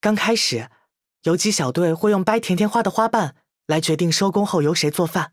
【模型】GPT-SoVITS模型编号106_男-secs